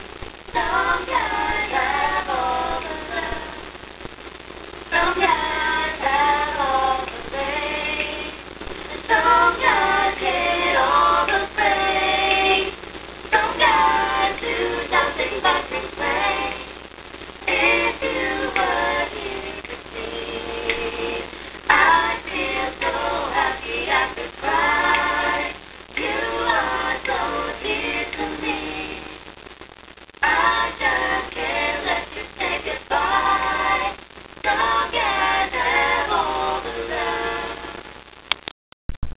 You're celebrity favorites give Fanvasion shout outs!